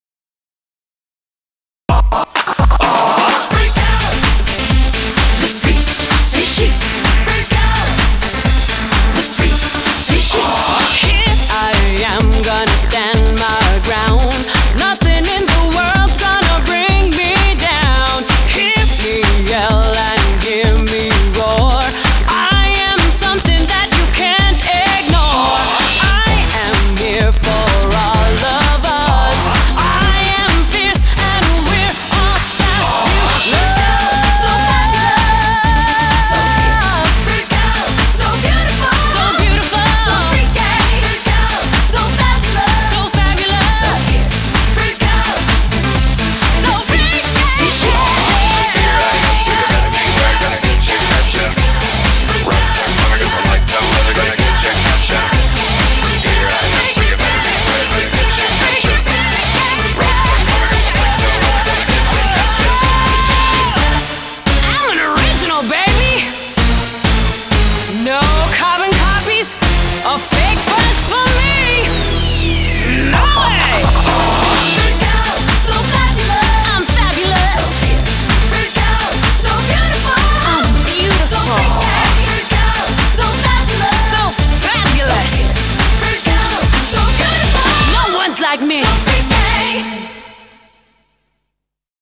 BPM128
Audio QualityLine Out